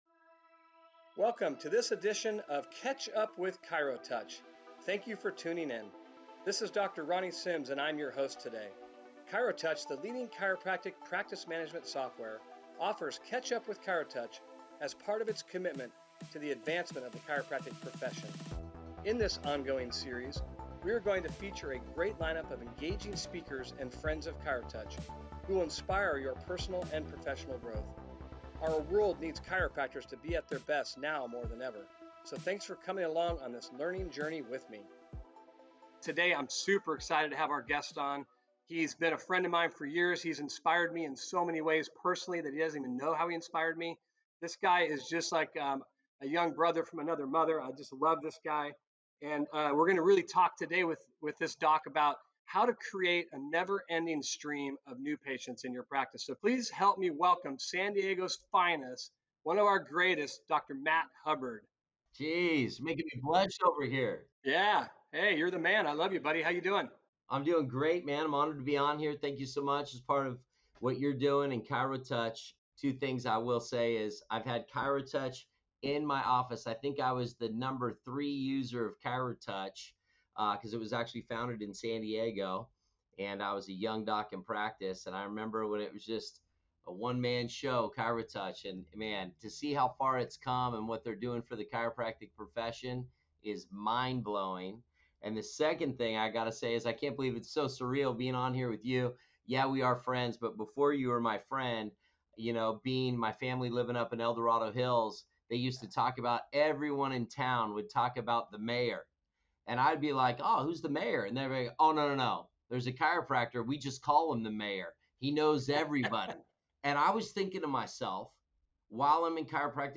Tune in to this educational and inspirational podcast series for conversations with chiropractic's most influential speakers.